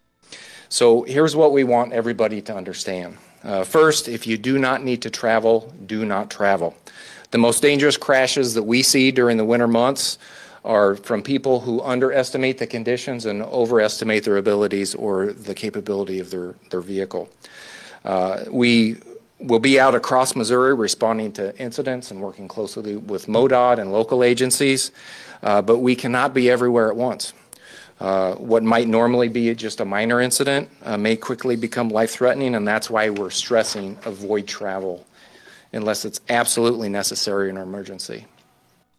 a news briefing that took place earlier today.